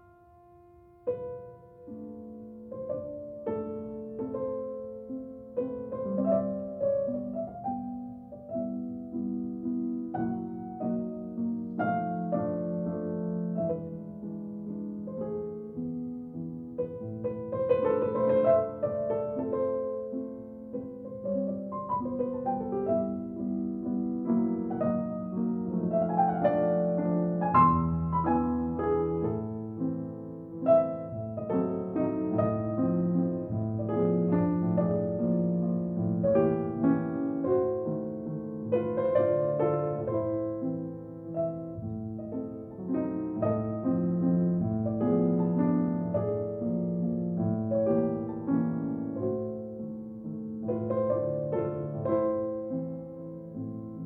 你聽見亮度與彩度均勻的音連成的樂句嗎？
那些震音，這天下有幾個人能(想以及有能力)這麼折磨自己呢？